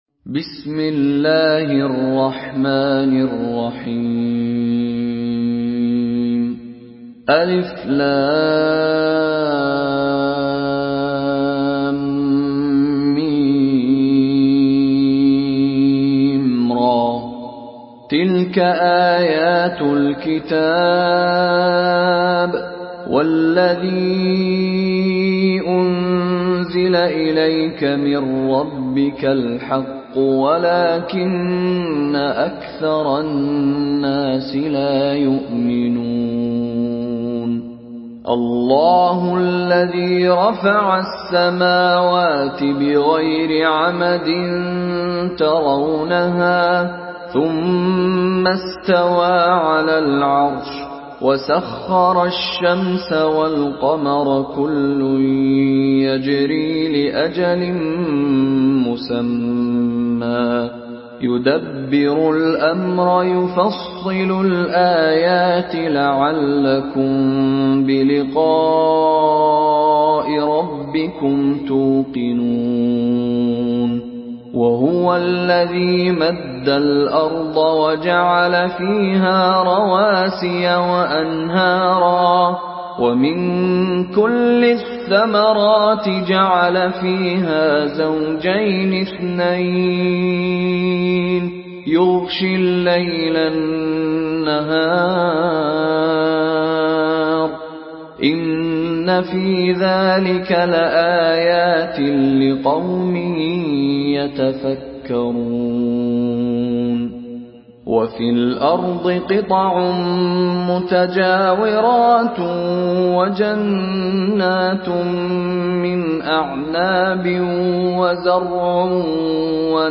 Surah Rad MP3 in the Voice of Mishary Rashid Alafasy in Hafs Narration
Surah Rad MP3 by Mishary Rashid Alafasy in Hafs An Asim narration.
Murattal Hafs An Asim